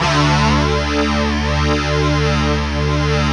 Index of /90_sSampleCDs/Optical Media International - Sonic Images Library/SI1_DistortGuitr/SI1_400 GTR`s